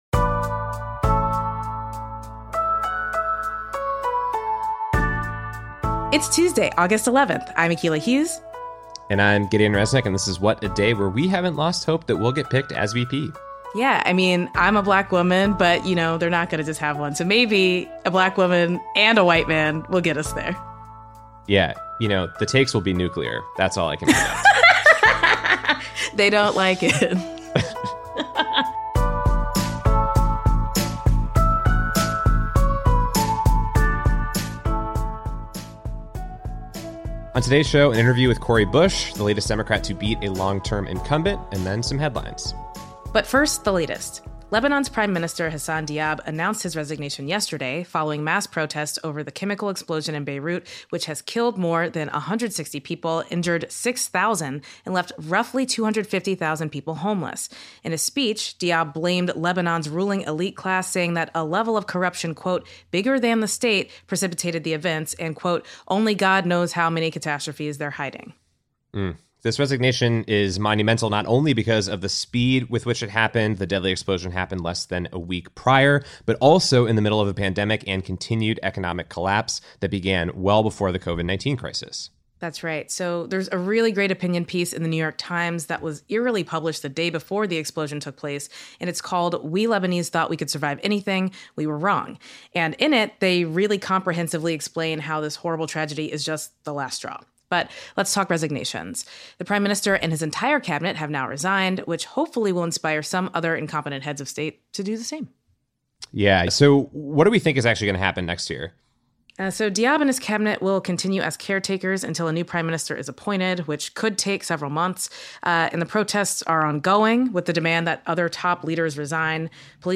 Five states hold primaries today, including Minnesota, where Rep. Ilhan Omar is running to keep her seat in Congress. The elections come a week after another progressive, Cori Bush, won a major upset in her race against a longterm incumbent in Missouri. We speak to Bush about her path to politics and check in on Justice Democrats, the political group that backed Bush, Omar, and a handful of other progressive Democrats since 2017.